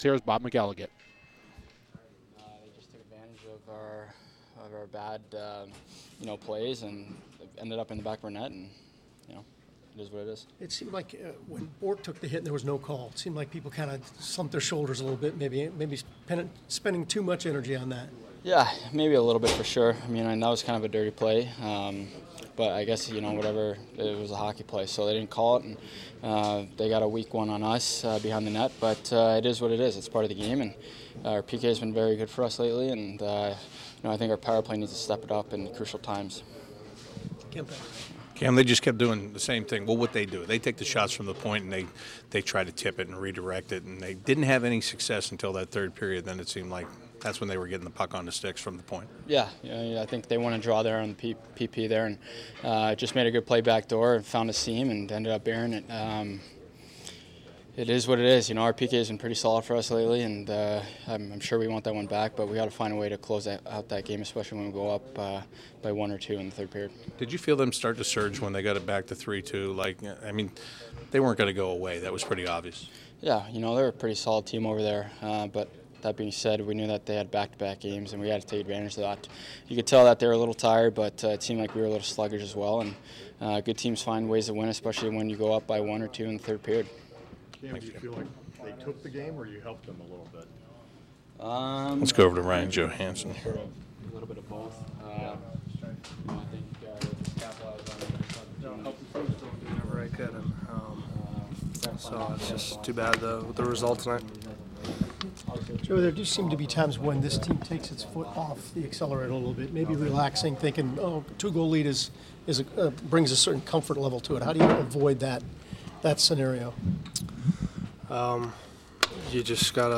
Cam Atkinson, Ryan Johansen and David Savard in the locker room after the Blue Jackets 5-3 loss to the San Jose Sharks